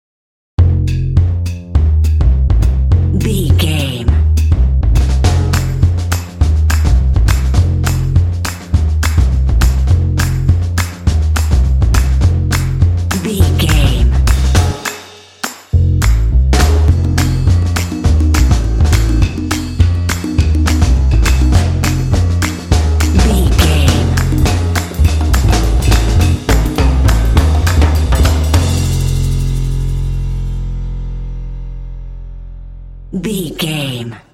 Aeolian/Minor
groovy
sultry
drums
bass guitar